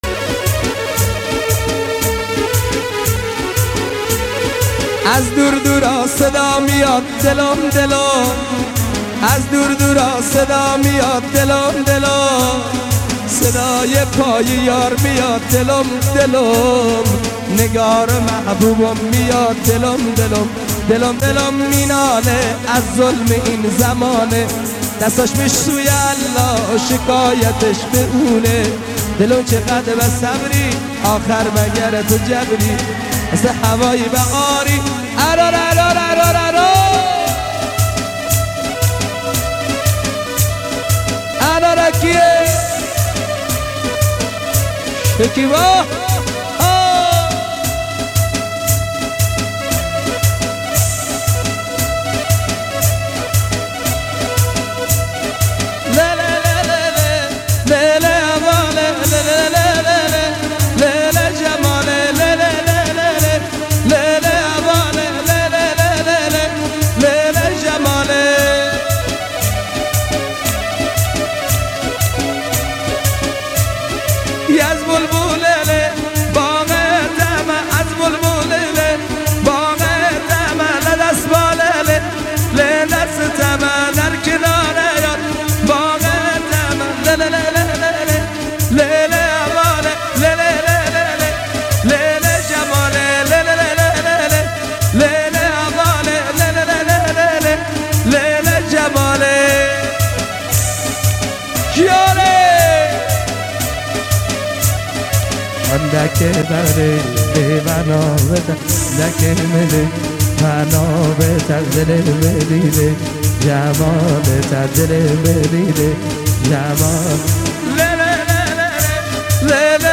یکی از خواننده های پرشور و پرانرژی کرمانجی